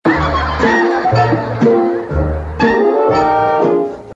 Play, download and share Chavo llorando original sound button!!!!
chavo-llorando.mp3